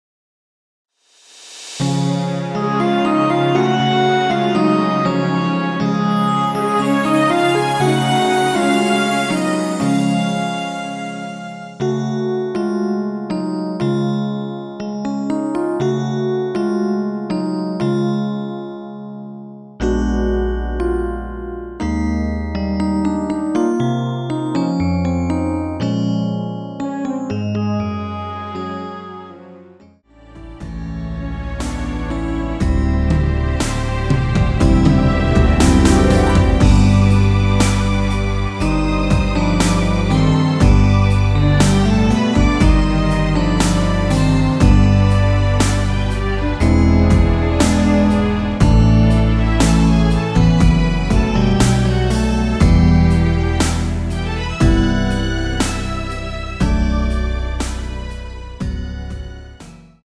축가로 많이 불려지는 곡 입니다.
◈ 곡명 옆 (-1)은 반음 내림, (+1)은 반음 올림 입니다.
앞부분30초, 뒷부분30초씩 편집해서 올려 드리고 있습니다.
중간에 음이 끈어지고 다시 나오는 이유는